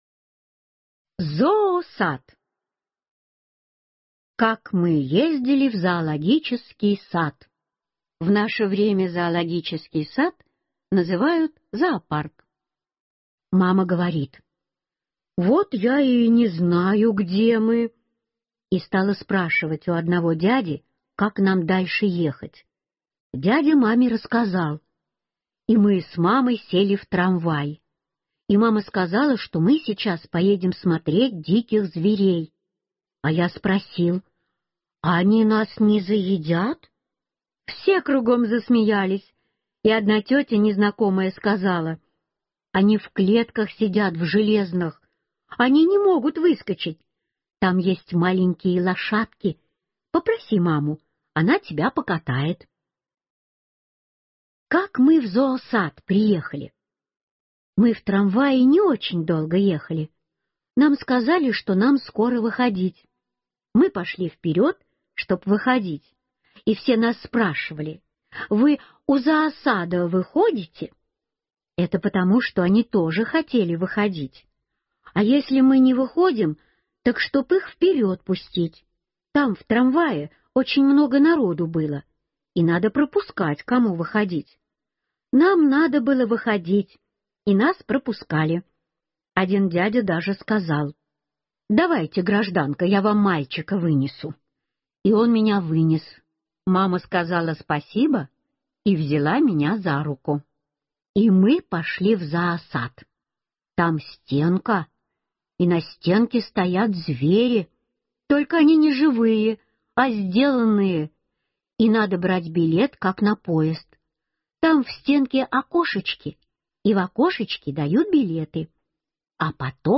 Зоосад - аудио рассказ Житкова - слушать онлайн